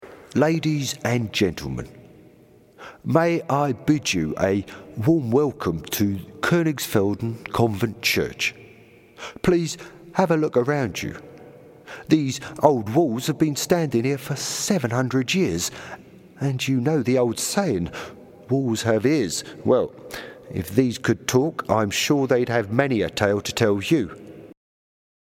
Character Voice